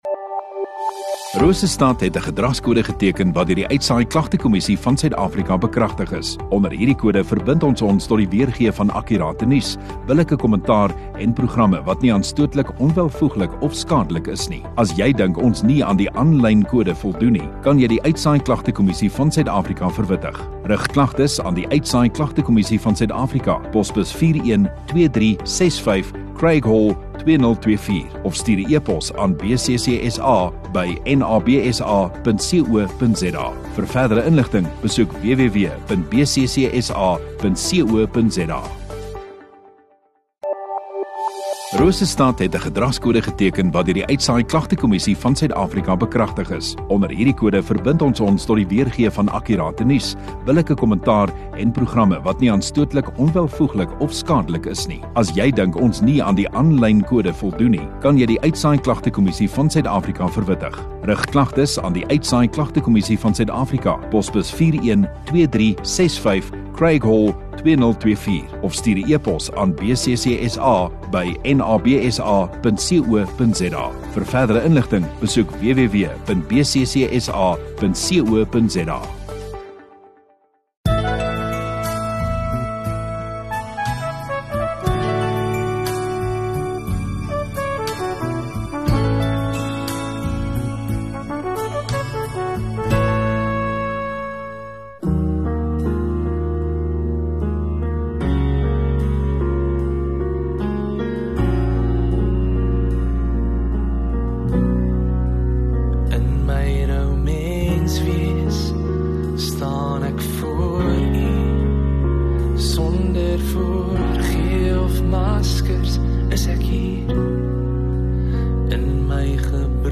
18 Jan Sondagaand Erediens